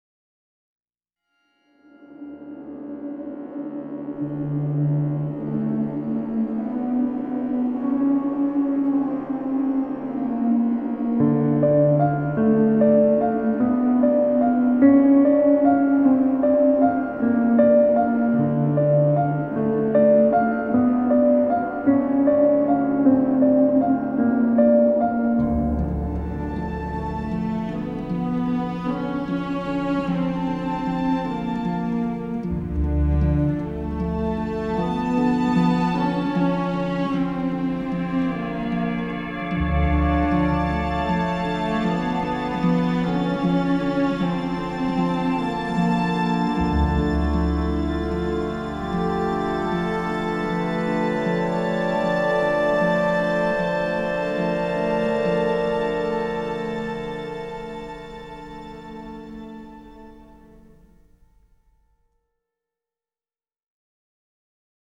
Banda sonora completa